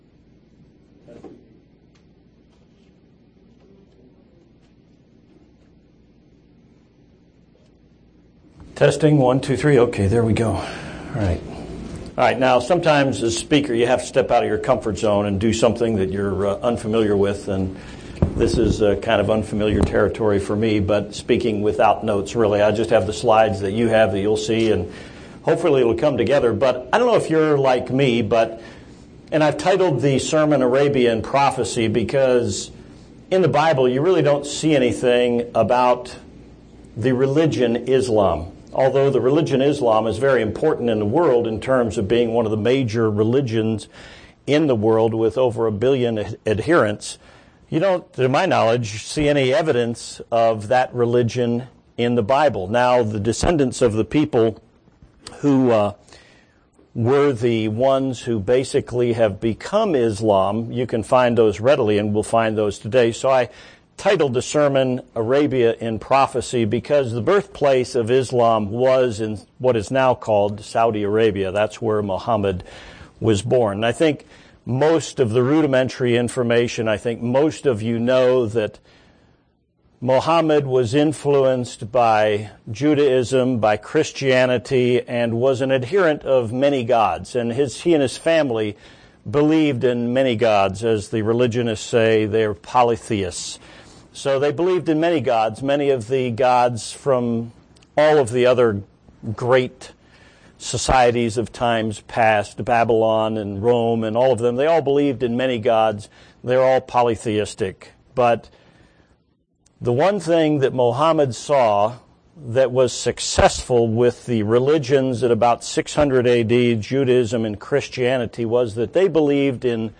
The sermon provides historical background and current information about the nations of the Middle East and other Islamic regions, as well as end time prophecies involving the Islamic world.